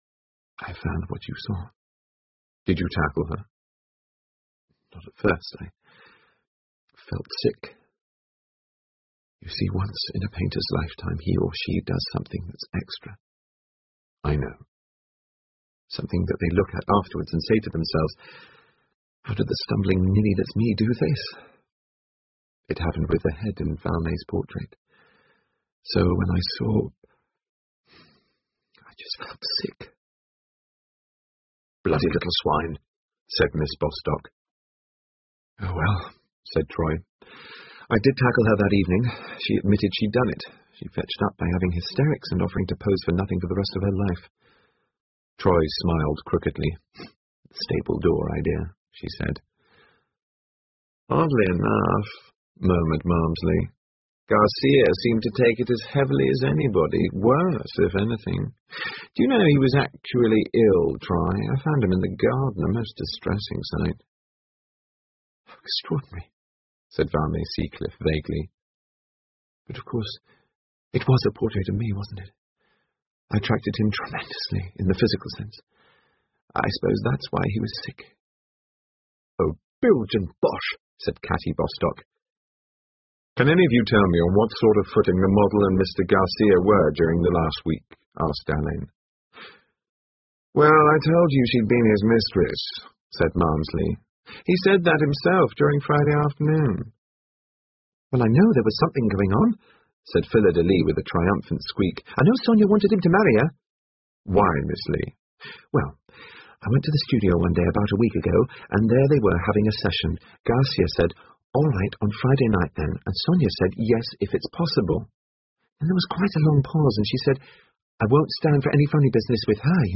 英文广播剧在线听 Artists in Crime 14 听力文件下载—在线英语听力室